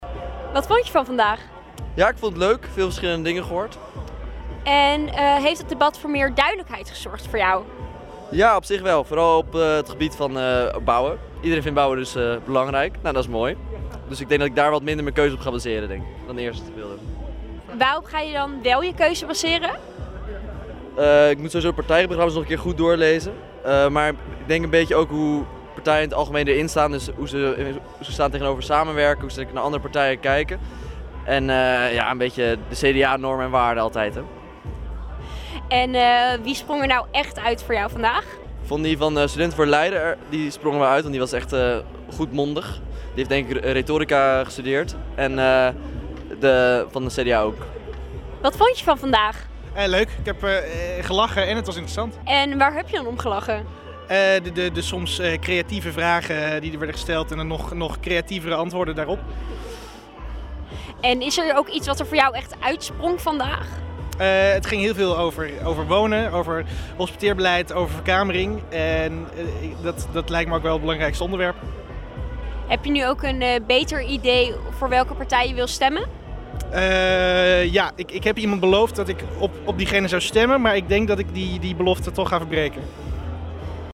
in gesprek met bezoekers van het debat: